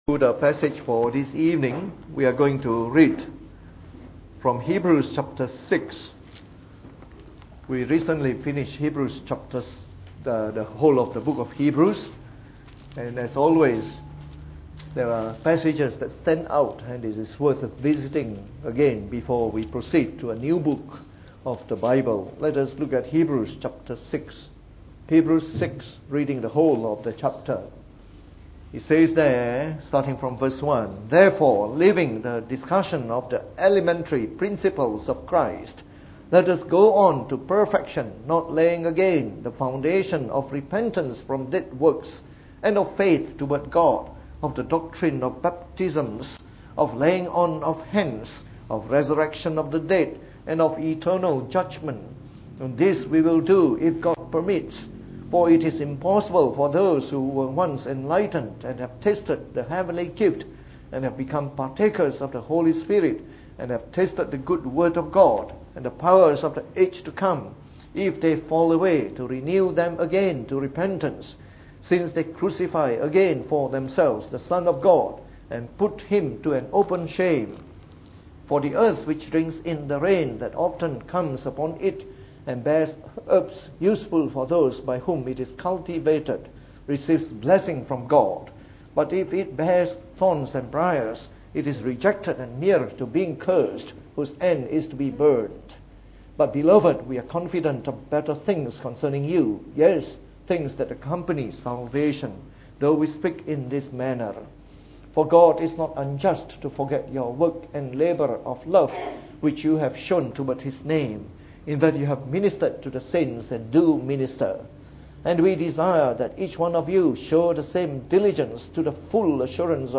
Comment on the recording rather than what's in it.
From our series on the “Epistle to the Hebrews” delivered in the Evening Service.